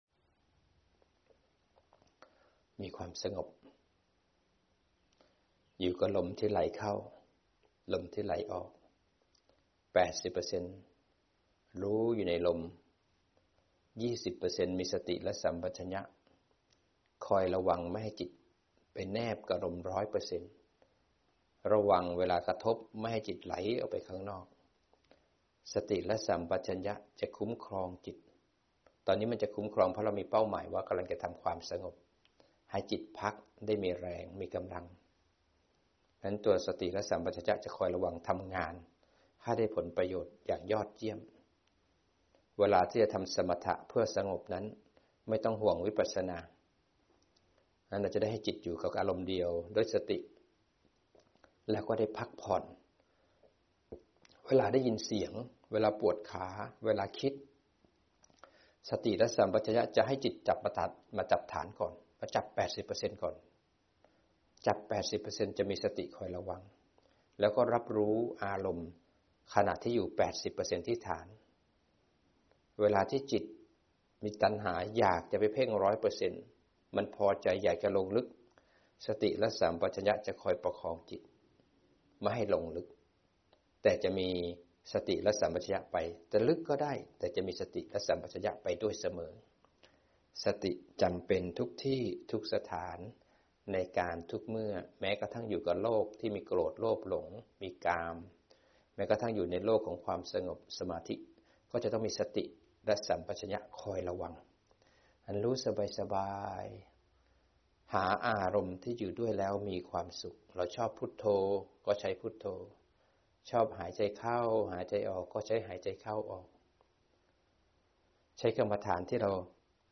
อาจารย์นำจิตทำความสงบเพิ่มพลังและถอยออกมาโยนิโสมนสิการเดินปัญญา